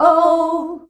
OUUH  E.wav